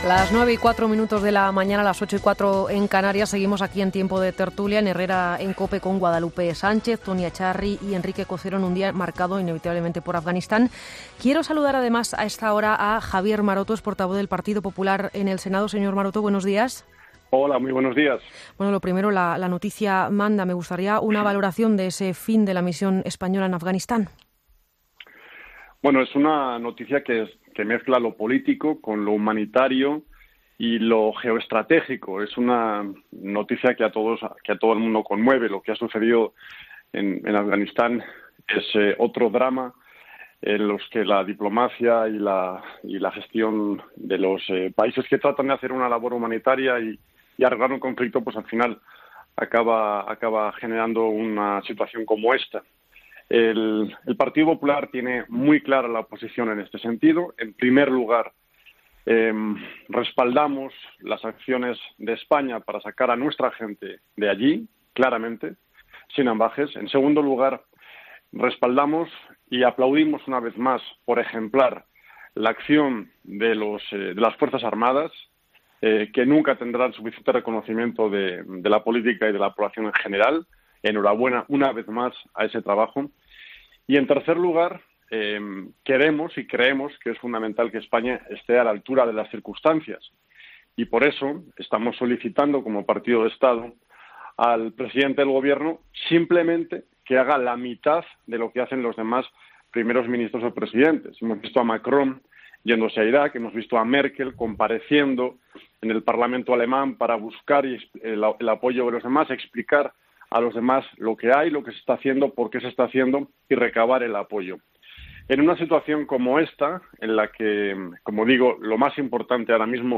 AUDIO: El portavoz del PP en el Senado critica en COPE que Sánchez no comparezca en el Congreso